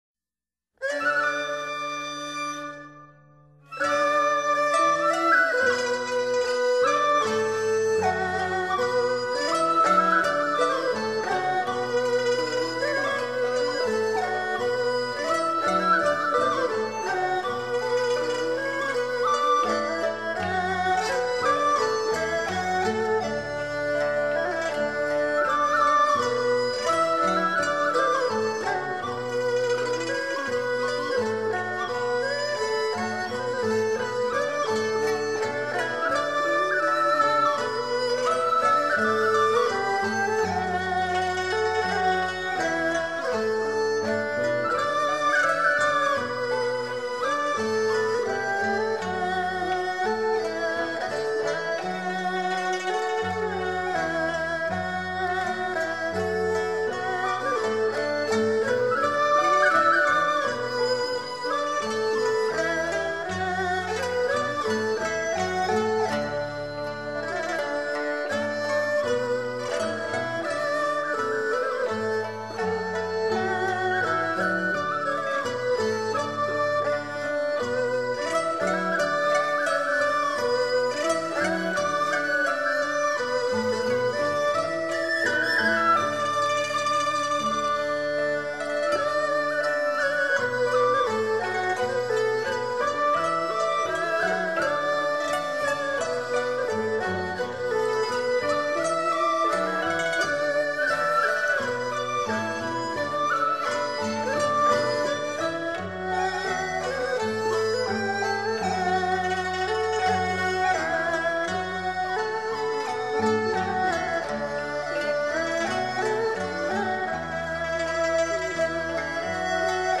全曲分为慢板和快板两部分，慢板轻盈优美；快板则热烈欢快，且层层加快，把喜庆推上高潮，具有浓厚的生活气息。